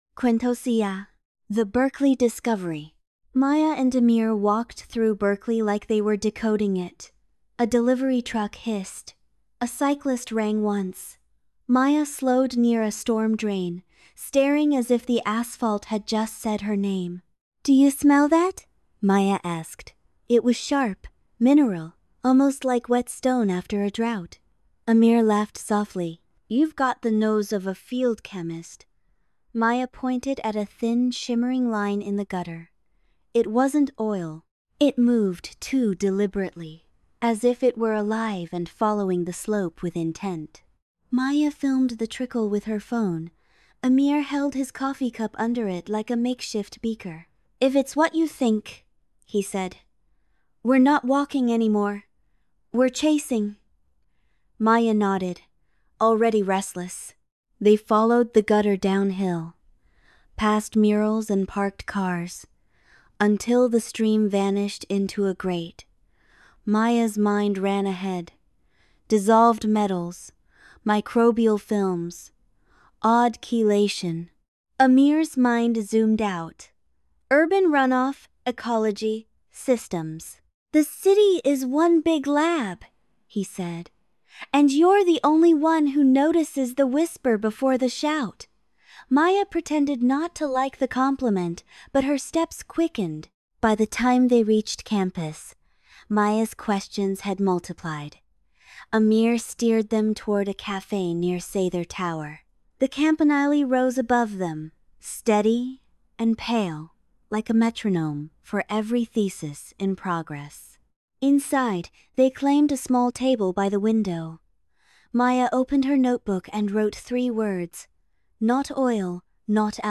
Narrated Audiobook